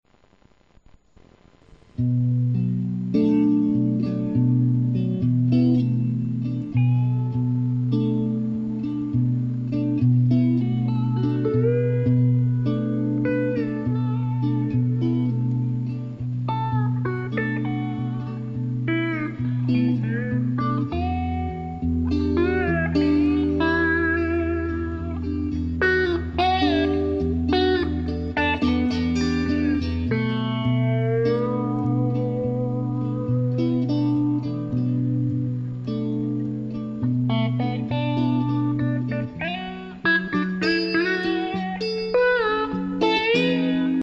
from Soulful rhythms to jumpin’ Rock n Roll
Vocals, guitar, harp and percussion